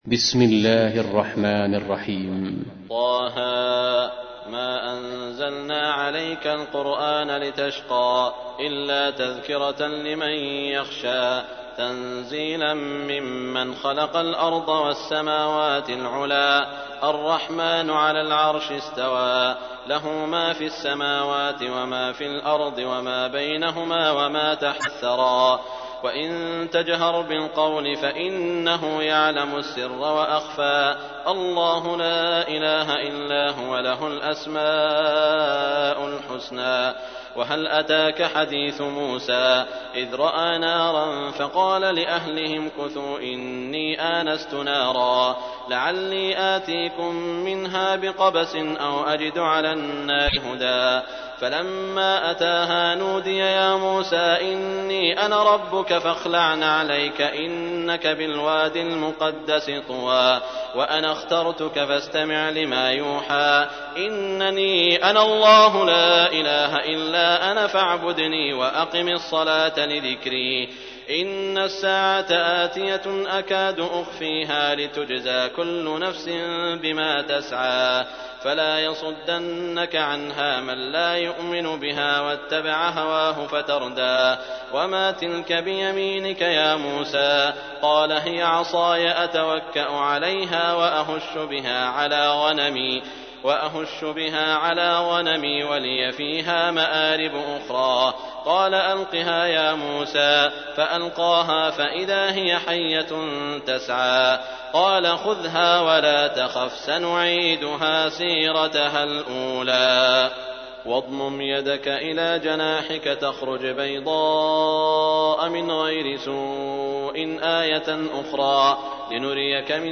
تحميل : 20. سورة طه / القارئ سعود الشريم / القرآن الكريم / موقع يا حسين